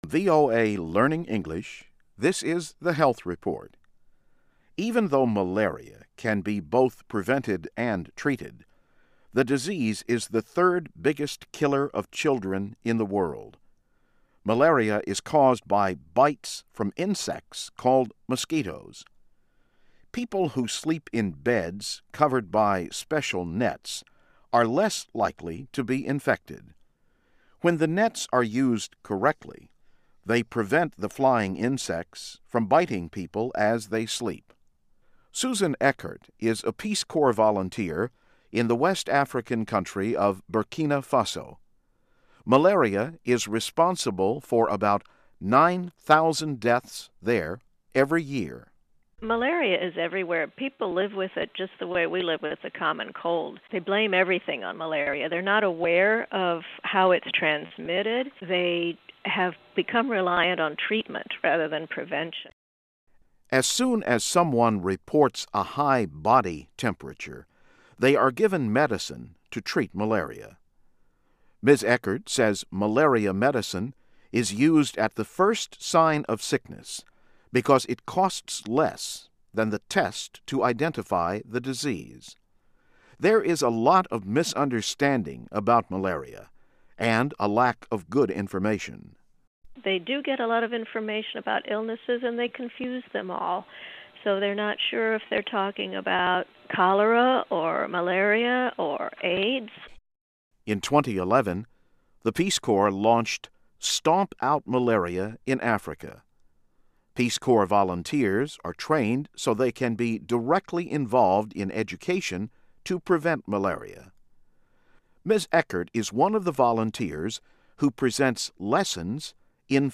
Learn English as you read and listen to a weekly show about developments in science, technology and medicine. Our stories are written at the intermediate and upper-beginner level and are read one-third slower than regular VOA English.